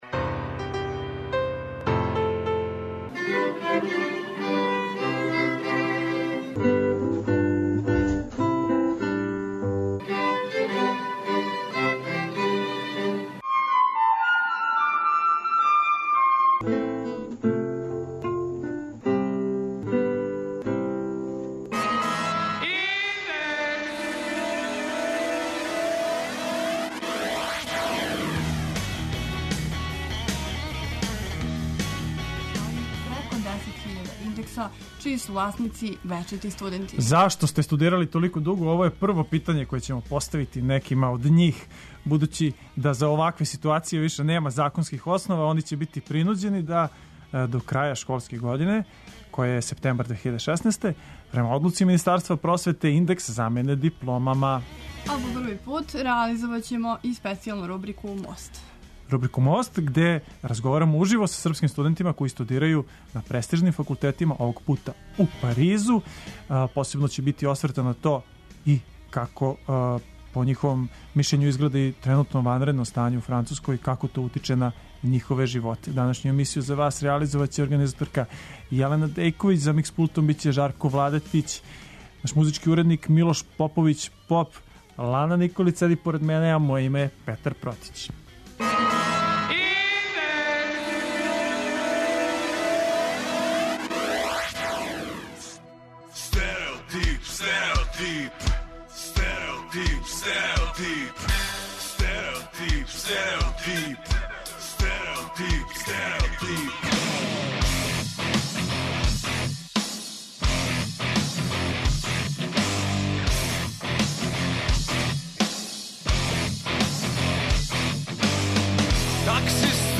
По први пут реализоваћемо специјалну рубрику ‘'Мост'' и разговараћемо уживо са српским студентима који студирају на престижним факултетима у Паризу. Посебно ће бити осврта на то како ће тренутно ванредно стање у Француској утицати на њихове животе.